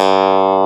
CLAV C2.wav